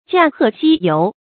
驾鹤西游 jià hè xī yóu 成语解释 死的婉称。